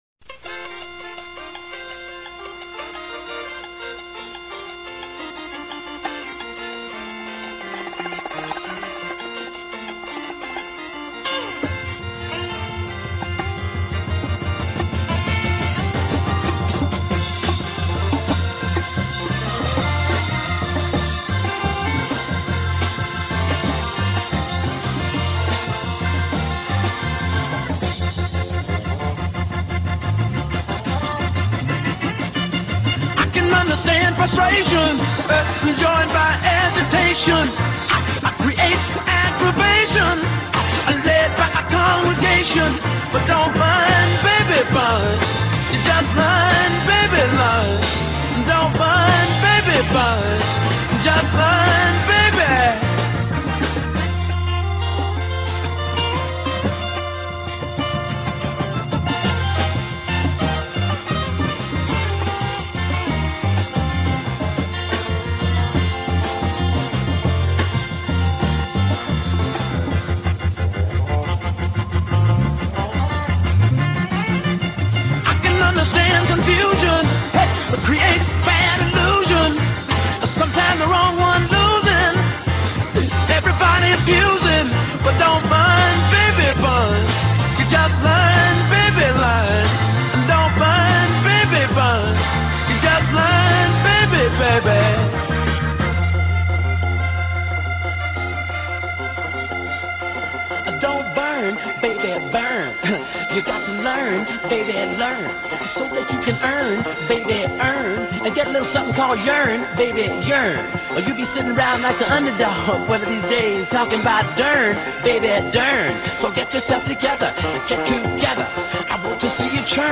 lead vocals, keyboards, everything
guitar, background vocals
lead and background vocals
drums, background vocals
saxophone, background vocals
trumpet, background vocals
bass guitar, background vocals